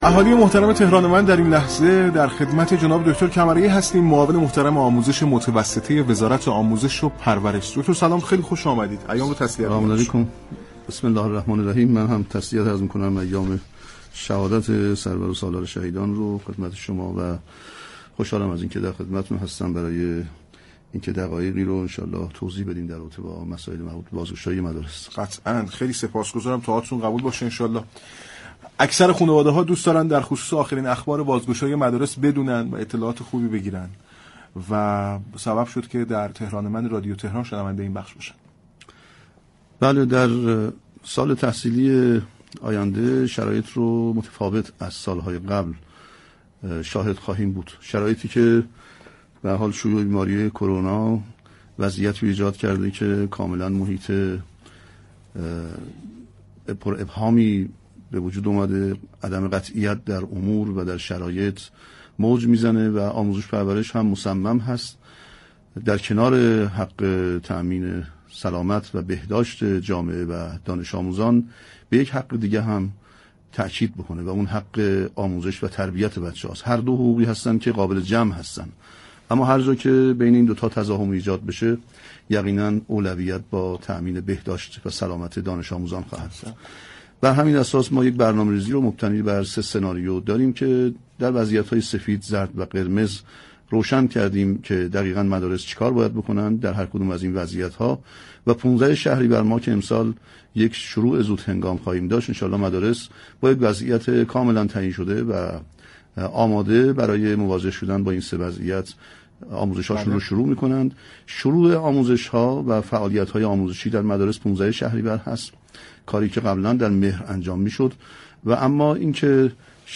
معاون آموزش متوسطه وزارت آموزش و پرورش در گفتگو با برنامه تهران من رادیو تهران با اشاره به اینكه سال تحصیلی پیش رو شرایطی متفاوت با سال های قبل دارد اظهار داشت: آموزش و پرورش در كنار امنیت و سلامت دانش آموزان بر آموزش و تربیت دانش آموزان هم تاكید دارد ولی در عین حال اولویت با بهداشت و سلامت جامعه دانش آموزی است.